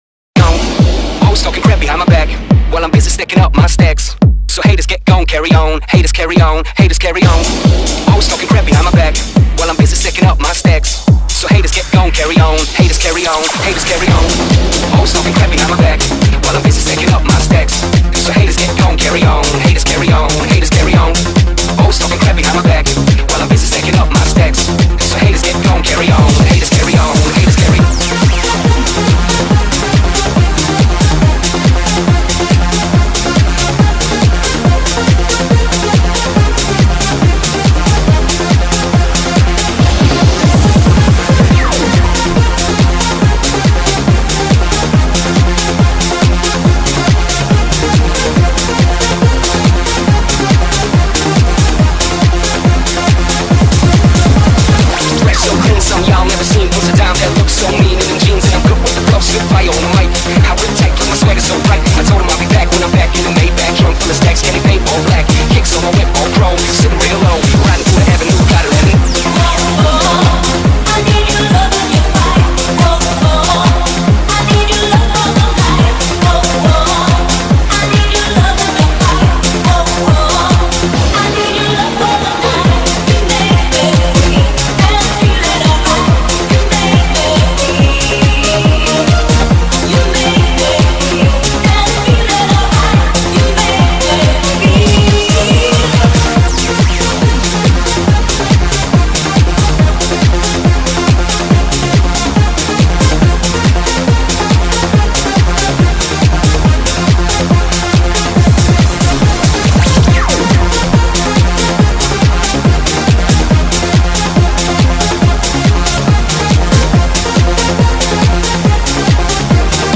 Magix_Music_Maker_vs__Beat_Box_ EuroDance Remix